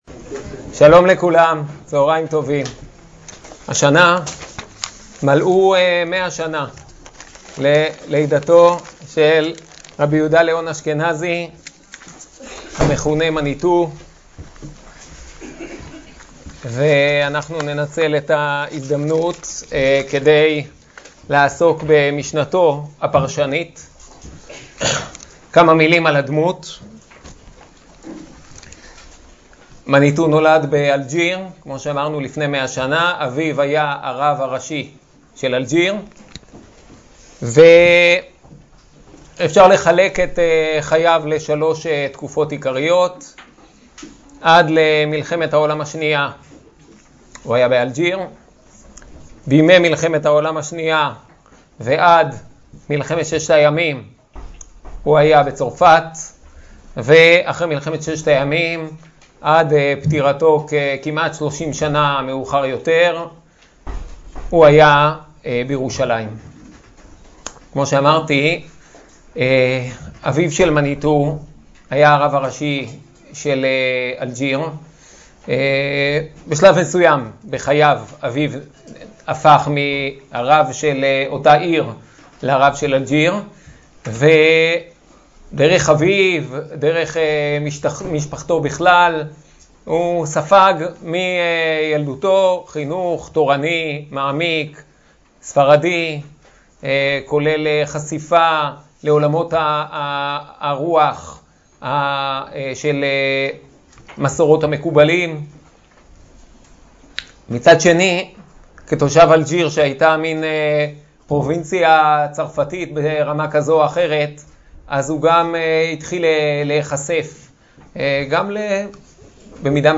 השיעור באדיבות אתר התנ"ך וניתן במסגרת ימי העיון בתנ"ך של המכללה האקדמית הרצוג תשפ"ב